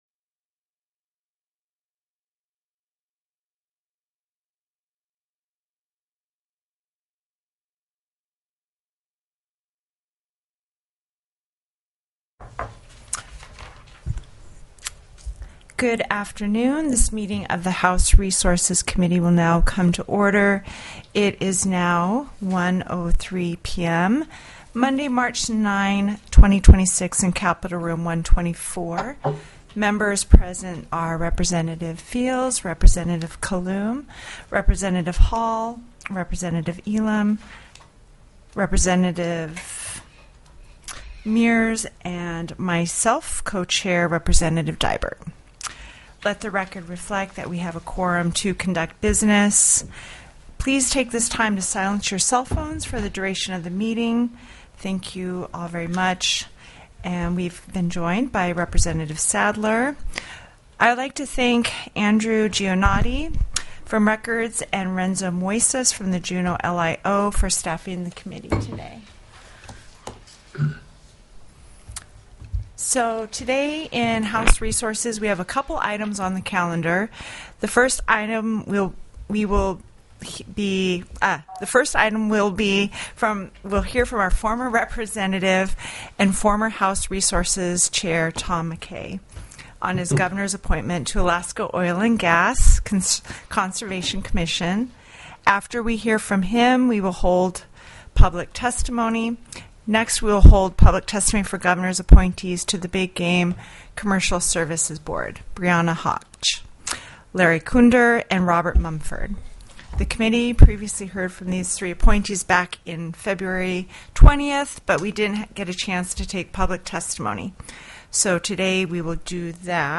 03/09/2026 01:00 PM House RESOURCES
The audio recordings are captured by our records offices as the official record of the meeting and will have more accurate timestamps.
Public Testimony